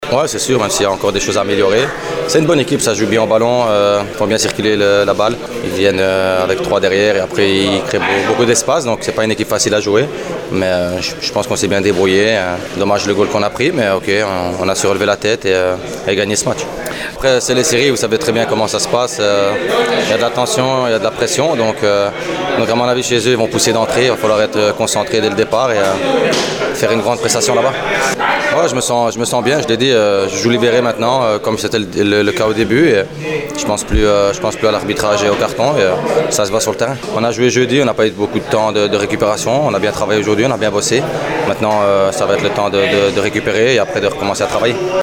Le interviste: